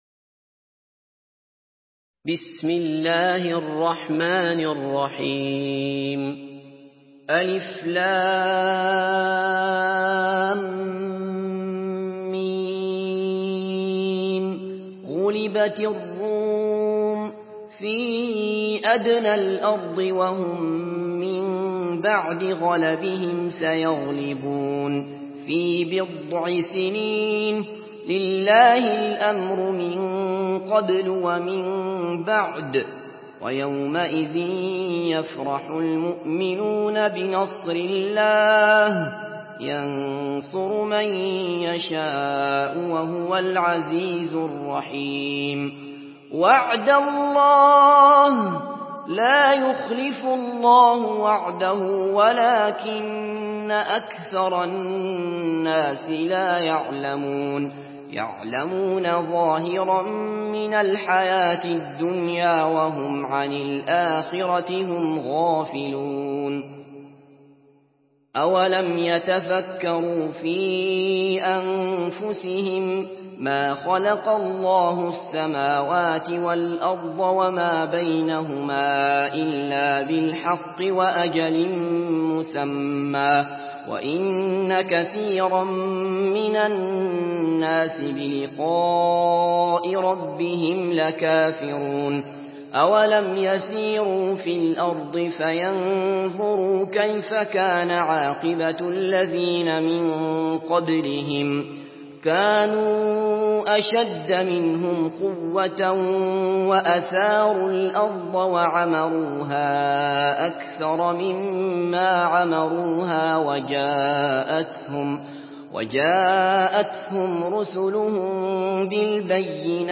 سورة الروم MP3 بصوت عبد الله بصفر برواية حفص
مرتل حفص عن عاصم